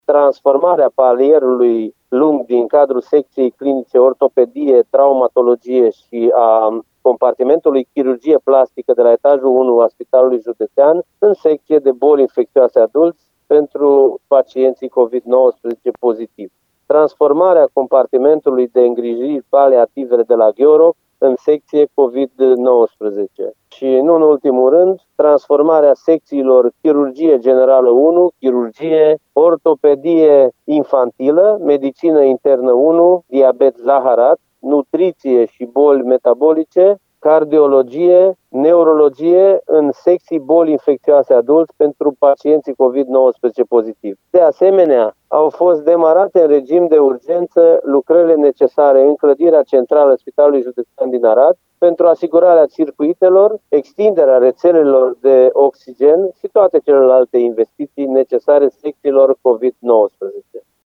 Președintele Consiliului Județean Arad, Iustin Cionca: